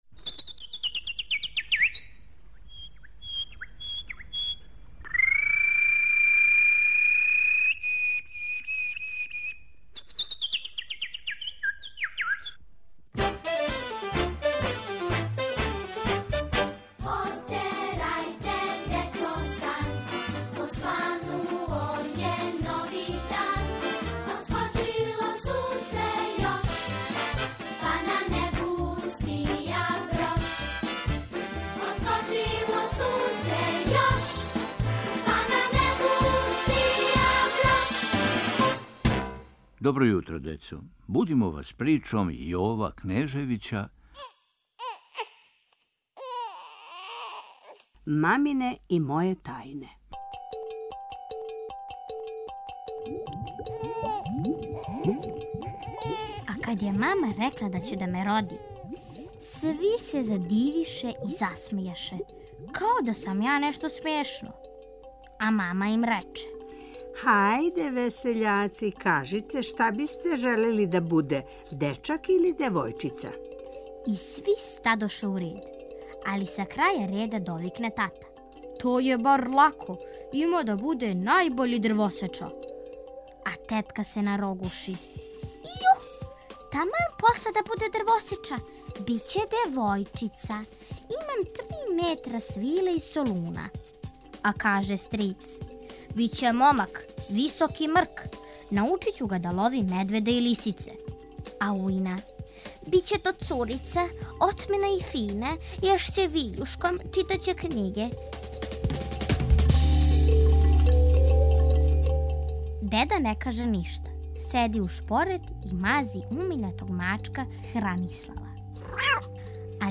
Будимо вас причом Јова Кнежевића "Мамине и моје тајне".